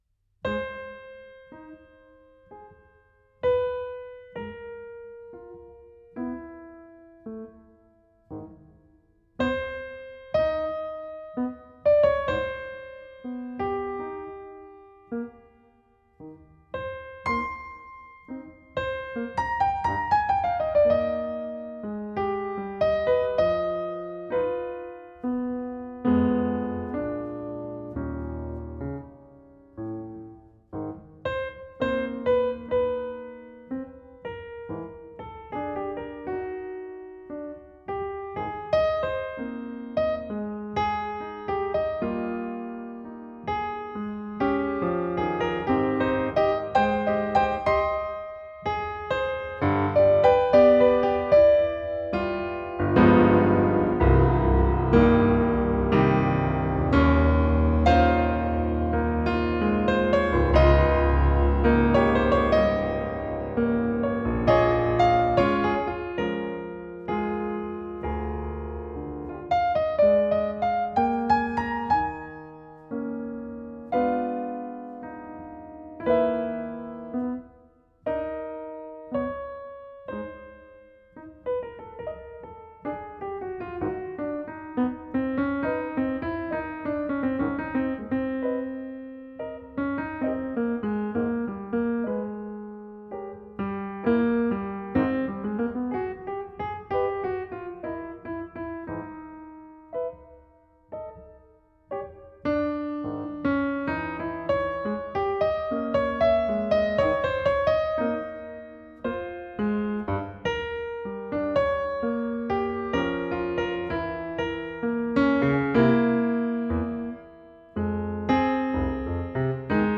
im Konzerthaus der Musikhochschule Detmold